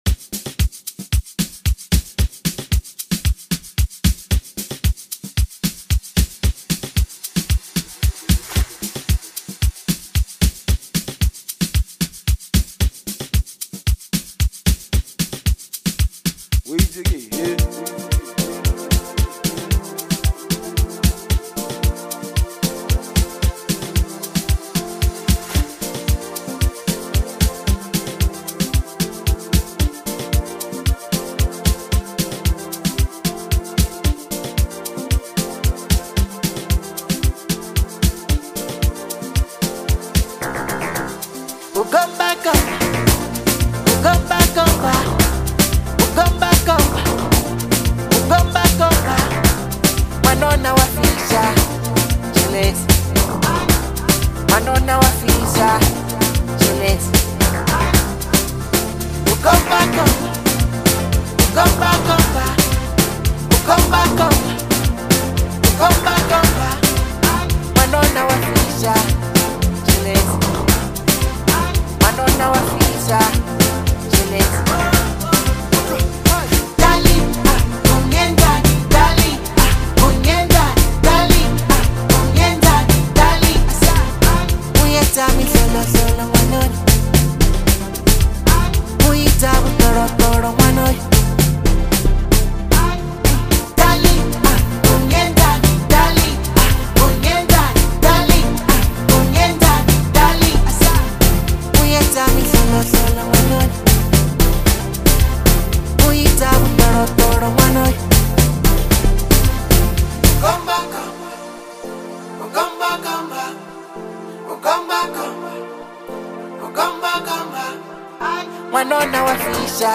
Amapiano
soulful vocals